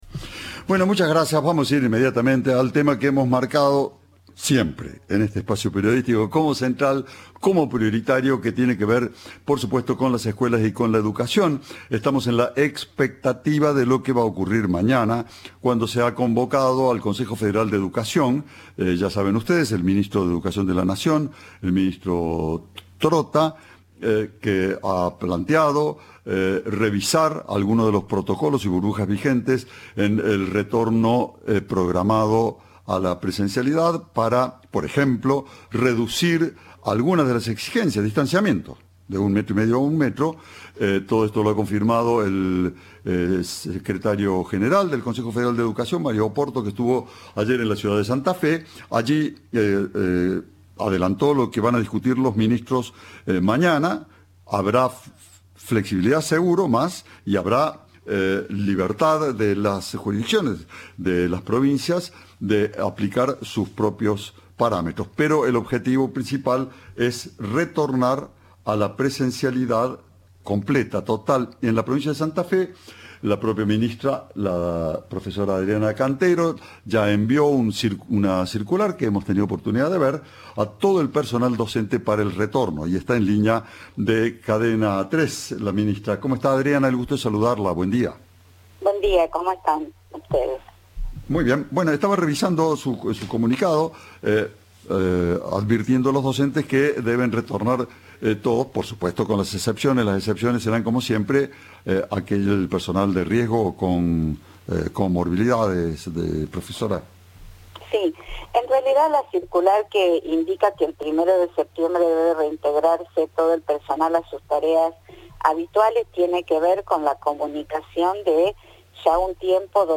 Adriana Cantero, ministra de Educación provincial, dijo a Cadena 3 que el personal está en condiciones de retomar sus actividades, ya que el 100% de titulares y reemplazantes fueron vacunados.
Entrevista